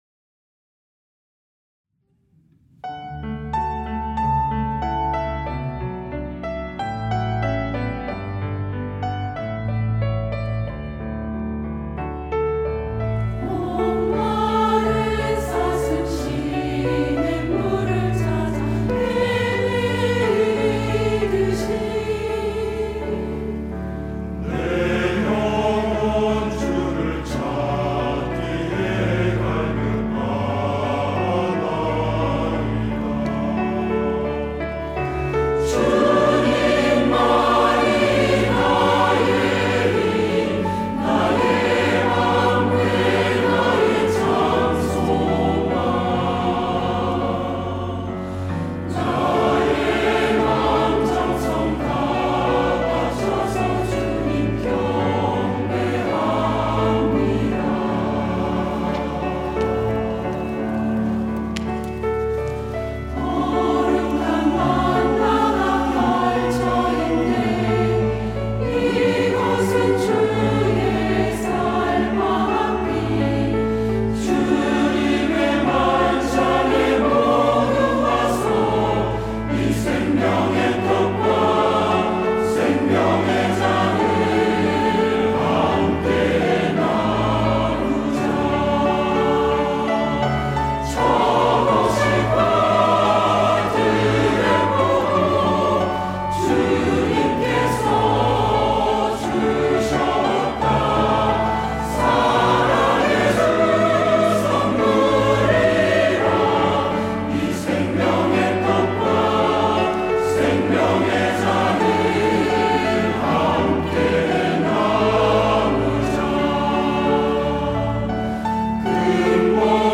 시온(주일1부) - 목마른 사슴이
찬양대 시온